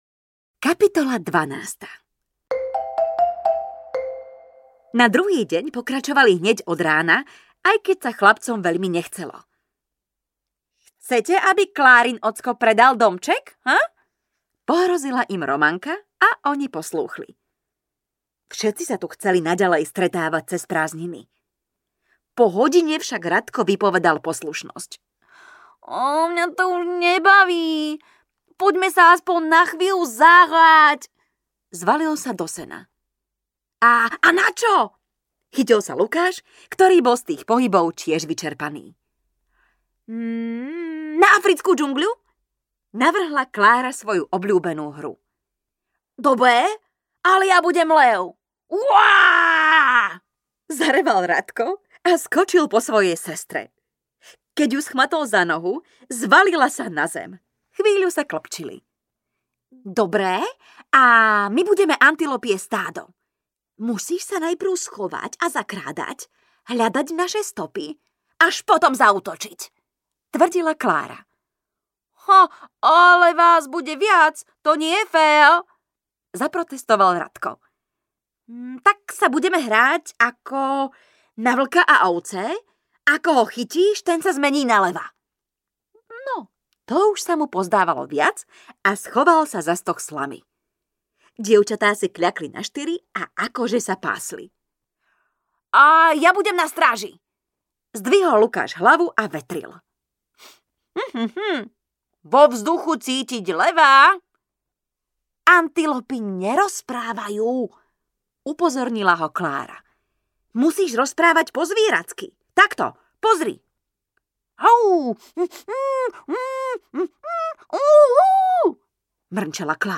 Klára a Tity audiokniha
Ukázka z knihy